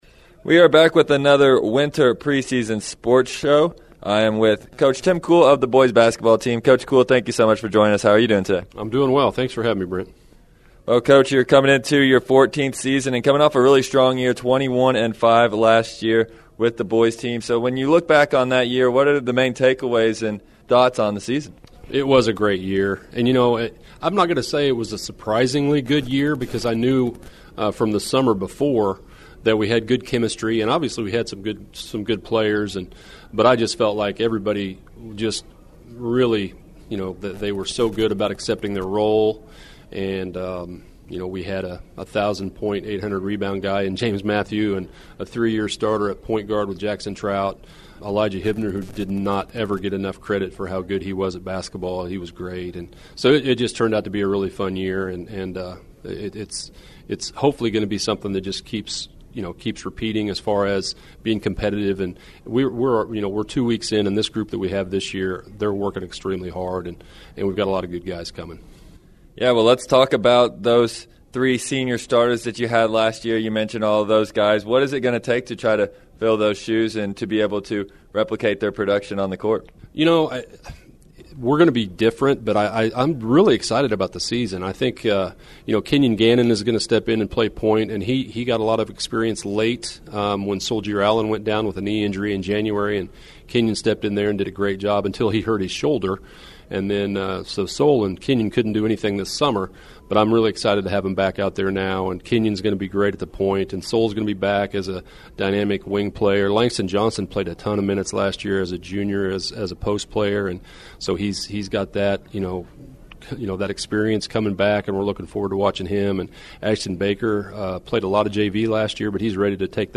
Chillicothe Hornets Boys Basketball Pre-Season Sports Show